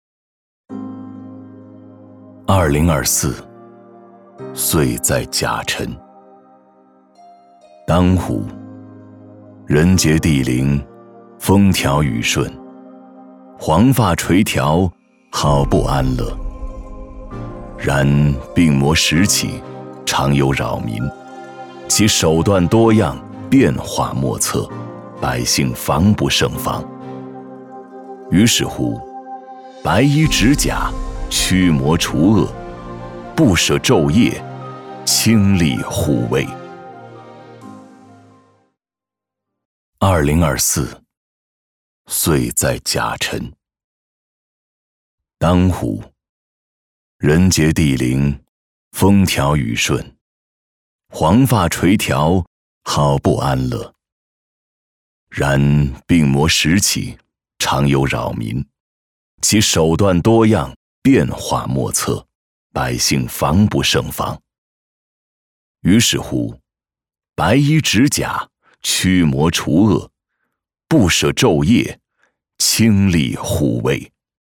擅长：专题片 广告
特点：大气浑厚 稳重磁性 激情力度 成熟厚重
风格:大气配音